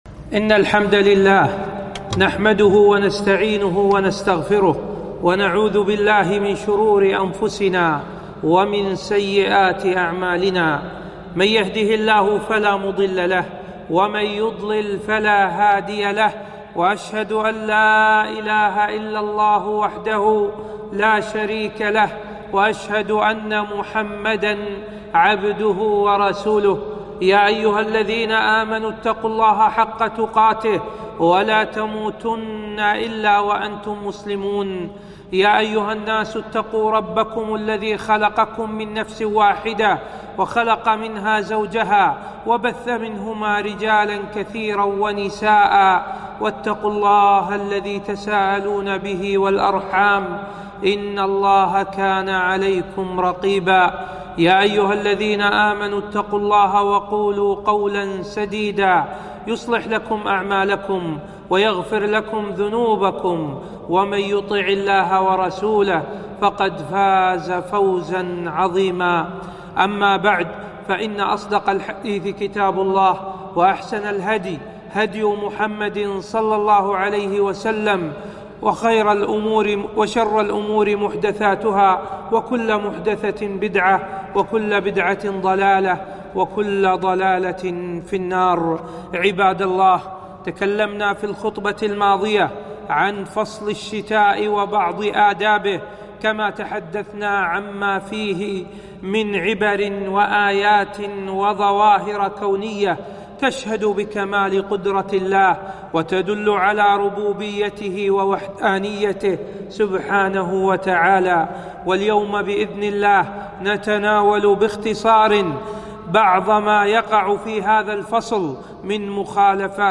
خطبة - أحــكام الشــتاء